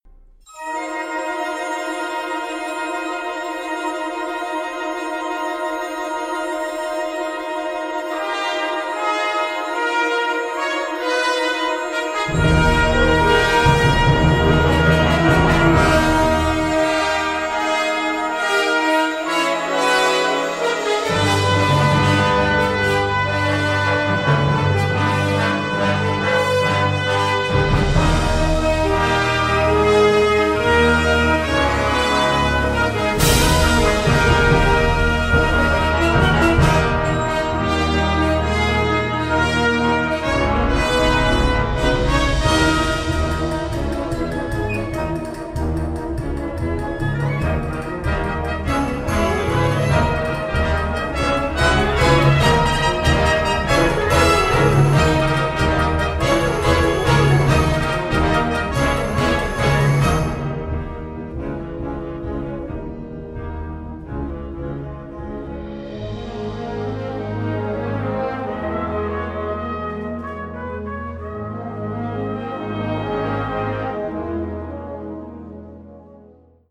Category Concert/wind/brass band
Subcategory modern popular band music
Instrumentation Ha (concert/wind band)